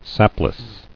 [sap·less]